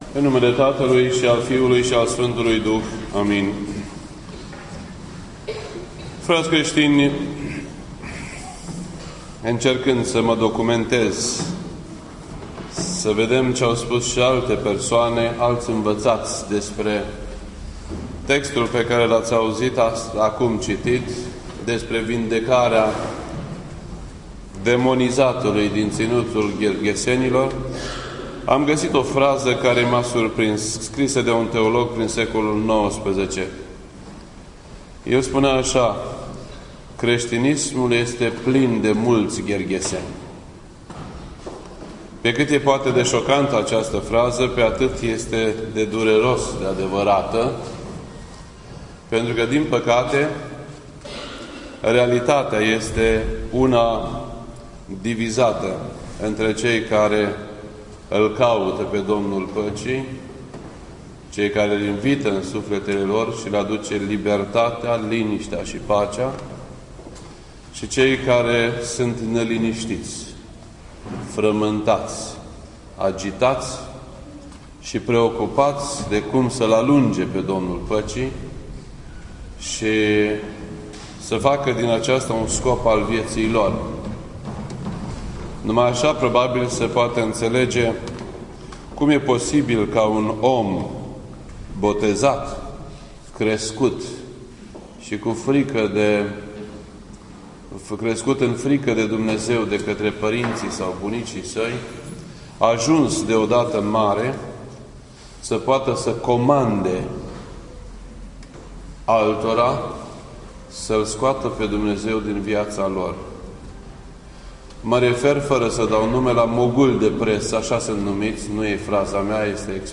This entry was posted on Sunday, October 25th, 2015 at 11:39 AM and is filed under Predici ortodoxe in format audio.